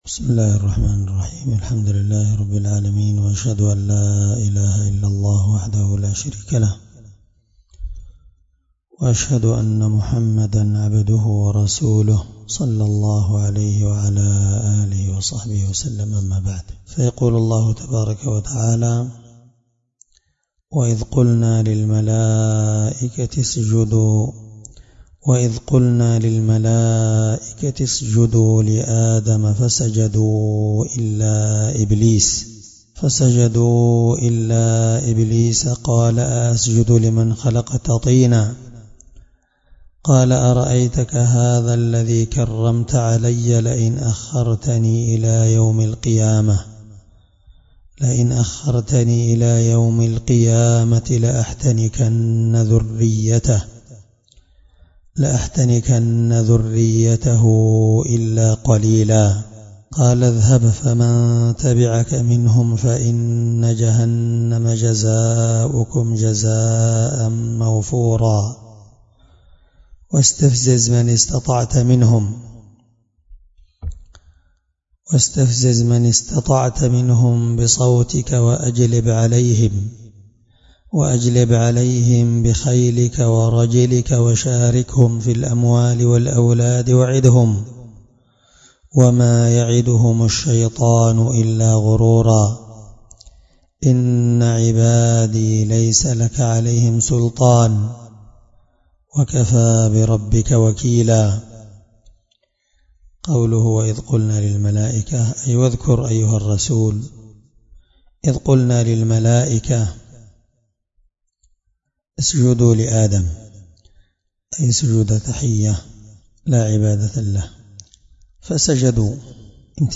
الدرس20 تفسير آية (61-65) من سورة الإسراء